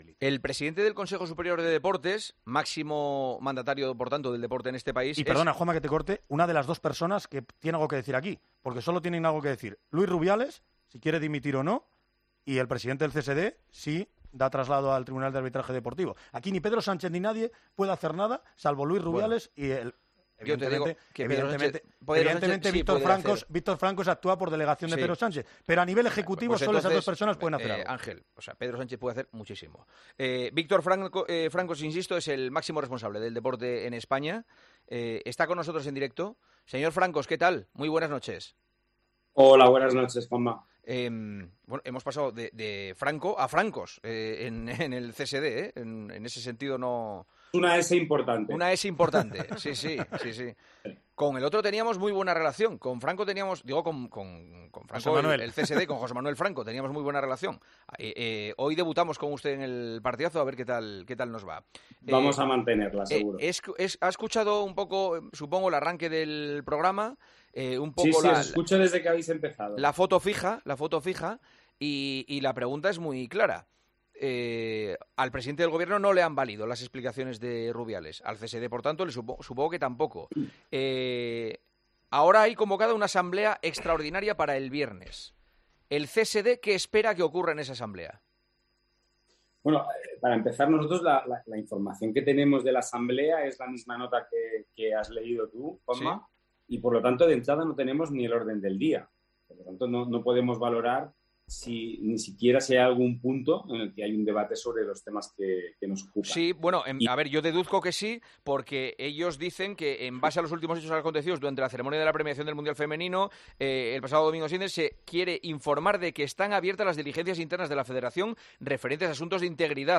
Juanma Castaño entrevistó al presidente del CSD para hablar de la polémica con Luis Rubiales tras su beso a Jenni Hermoso en la entrega de medallas de...